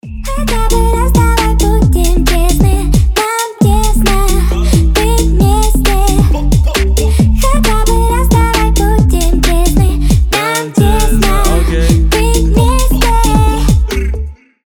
• Качество: 320, Stereo
дуэт
UK garage